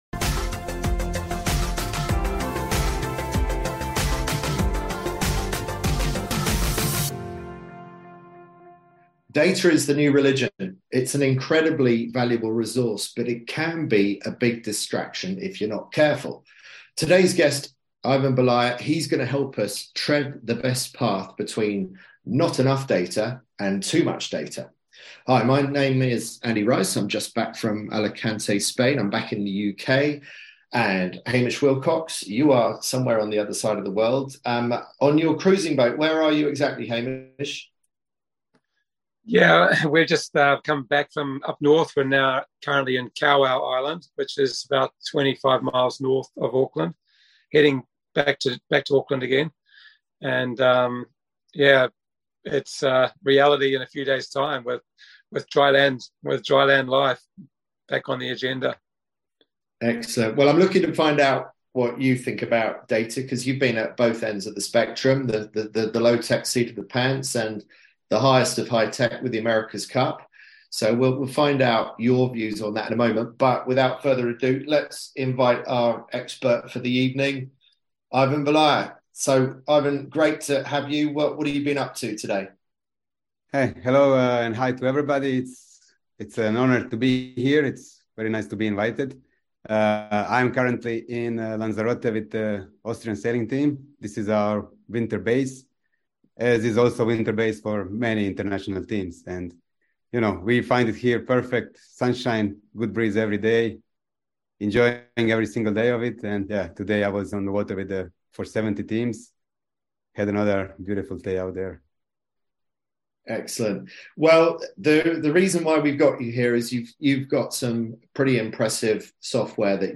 Our weekly Q&A sessions on Zoom, to answer your burning questions and enlighten you on your Road To Gold
Live Weekly Q&A Coaching Calls